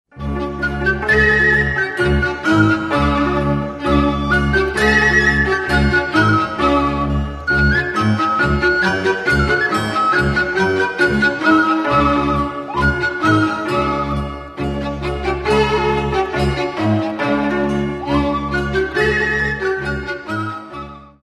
Народная